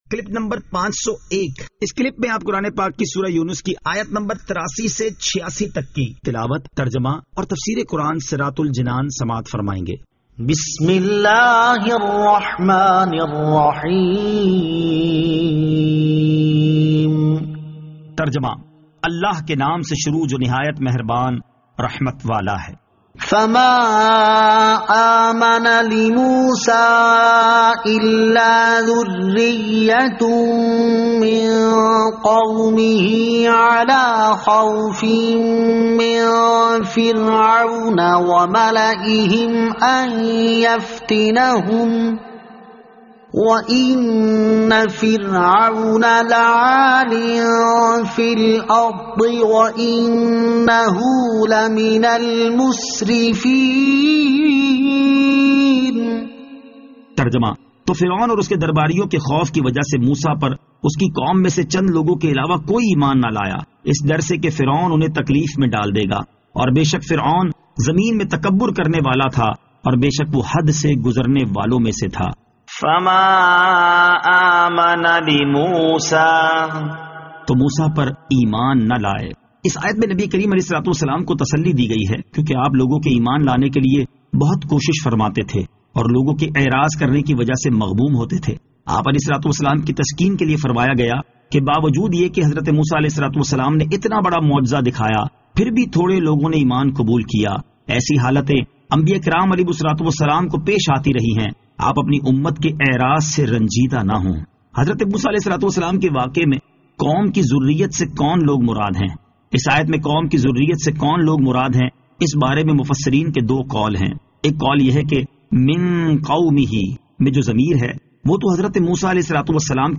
Surah Yunus Ayat 83 To 86 Tilawat , Tarjama , Tafseer